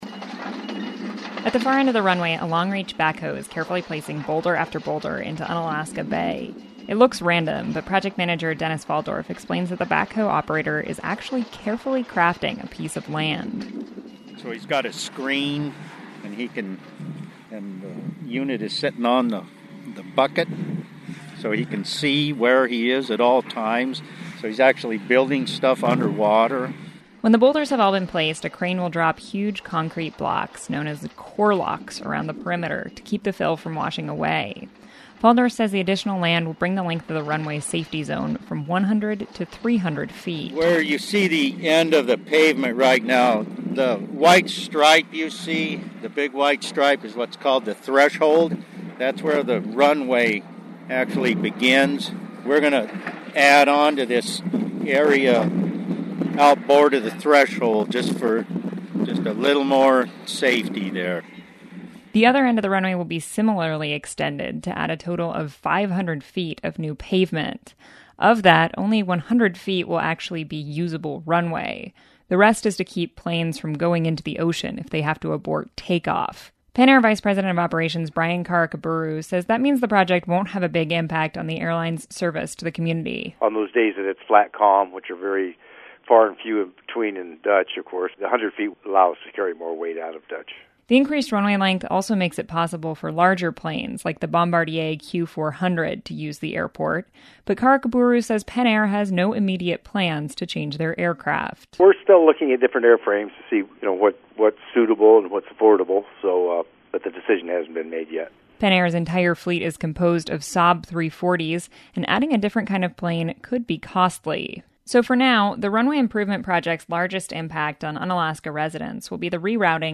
toured the construction site, and has this report on the project’s progress.